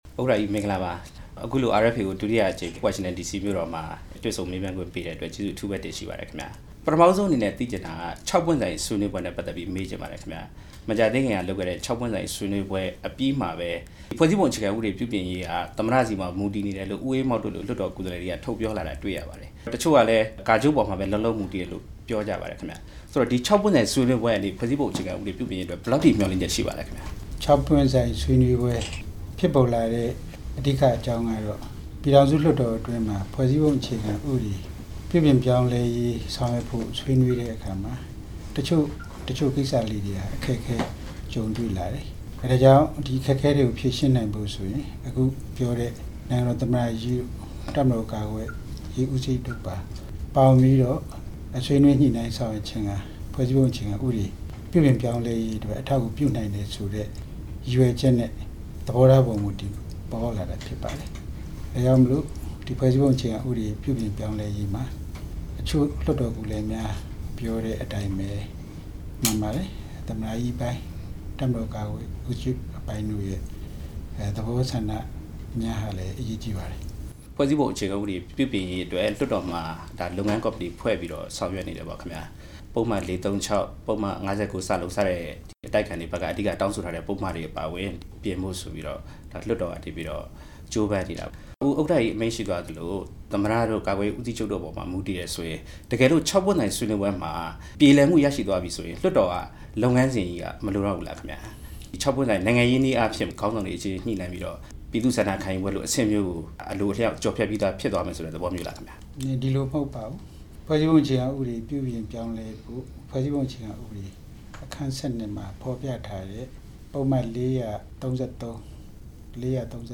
သူရဦးရွှေမန်းနဲ့ မေးမြန်းချက်